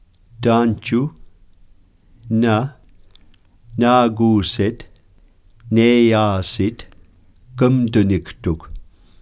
tanjiw_slow.wav